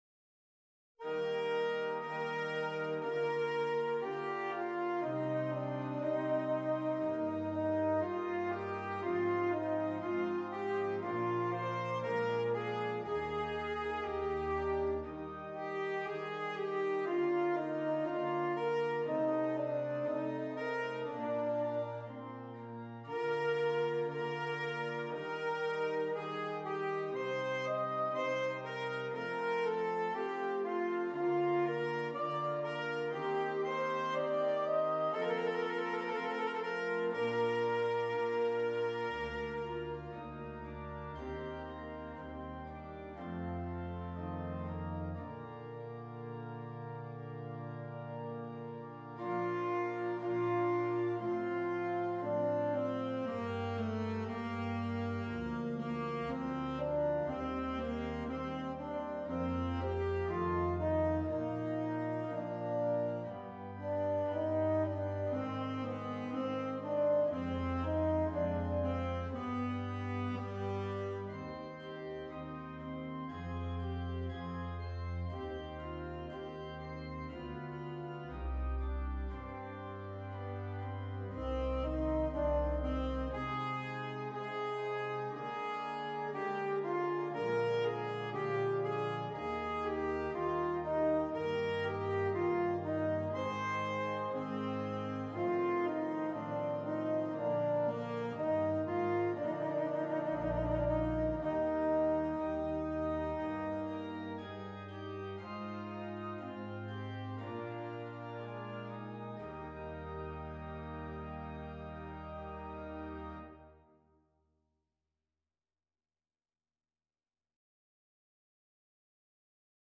Alto Saxophone and Keyboard